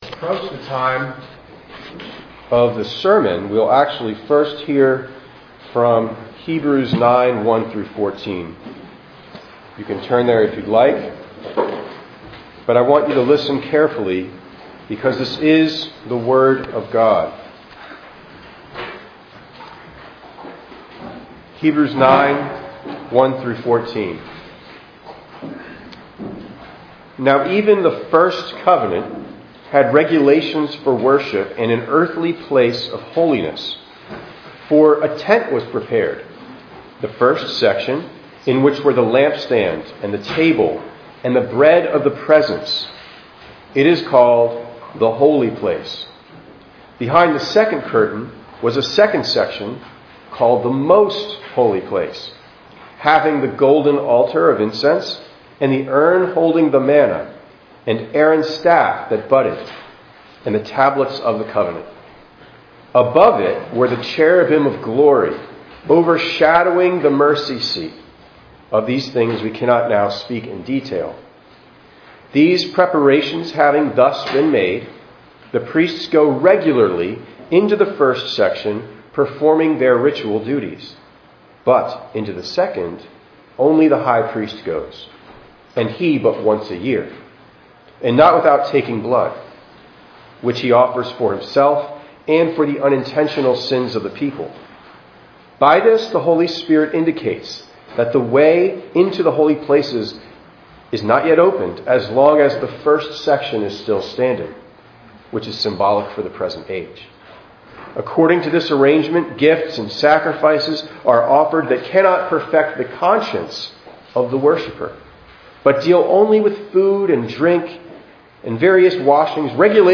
10_8_23_ENG_Sermon.mp3